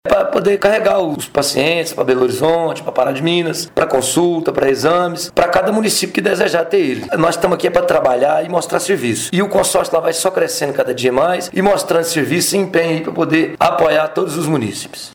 O presidente do Cispará, que também é prefeito em São José da Varginha, afirmou que os novos ônibus irão agilizar bastante a prestação de serviços dos municípios conveniados com o consórcio de saúde. A frota vai subir de 10 para 15 veículos: